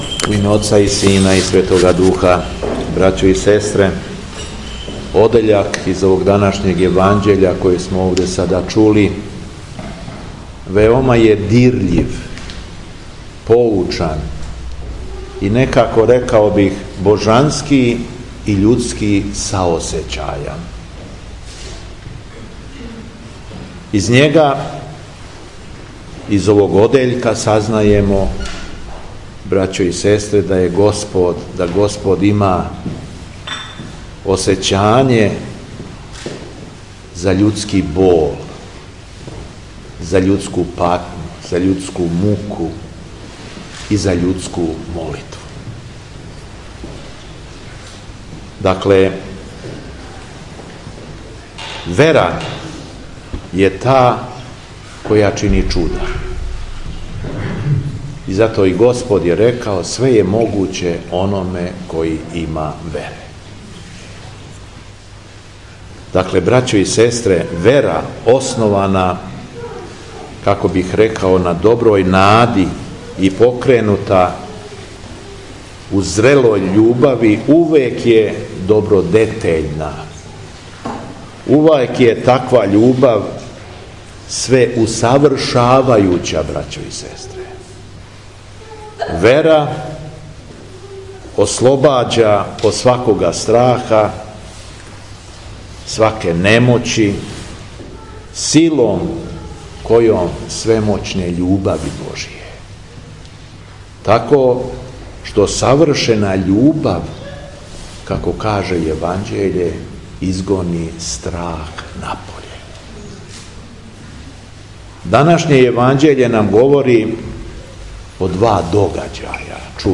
СВЕТА АРХИЈЕРЕЈСКА ЛИТУРГИЈА У ЈАГОДИНСКОЈ ЦРКВИ СВЕТЕ ПЕТКЕ
Беседа Његовог Преосвештенства Епископа шумадијског г. Јована